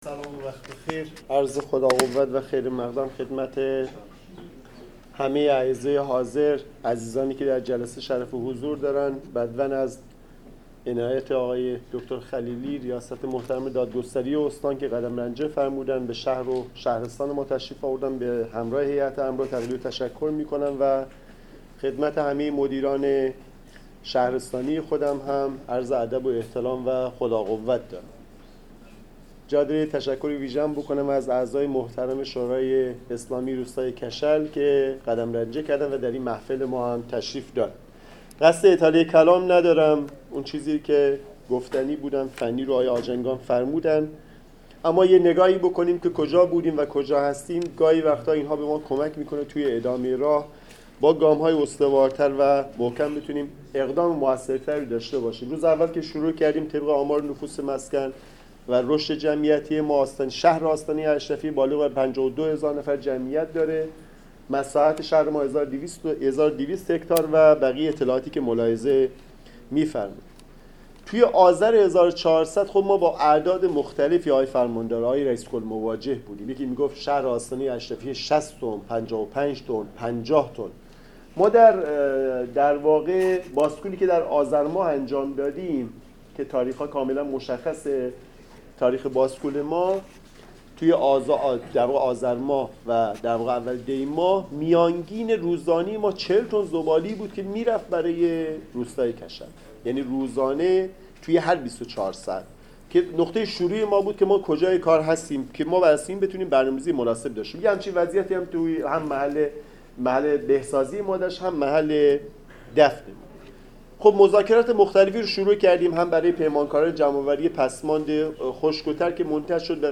شهردار آستانه اشرفیه در نشست مسئولین قضایی شهرستان و فرماندار در مجتمع شیمی کود آلی معراج در شهرک صنعتی پرکاپشت یاور زاده از کاهش ۳۳ درصدی زباله به محل دفن زباله در کشل آزاد سرا این شهرستان خبرداد.